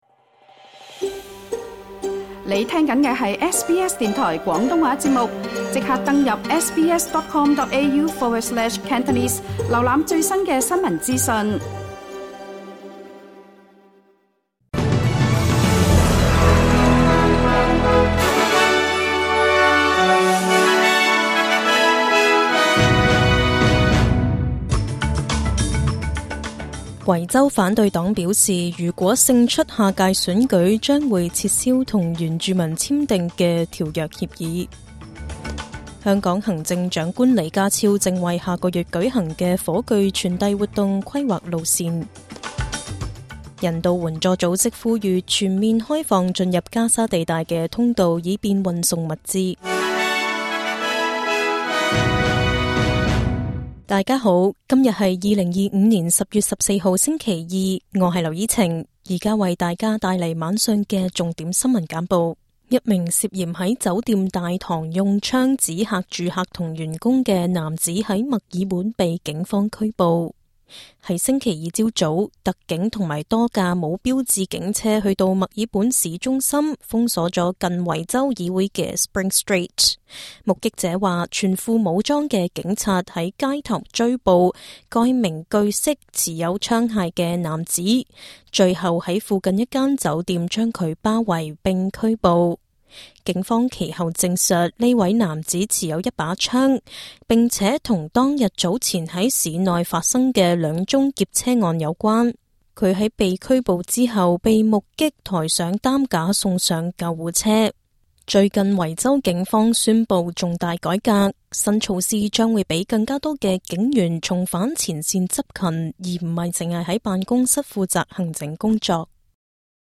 SBS 晚間新聞（2025年10月14日）